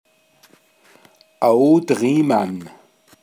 pinzgauer mundart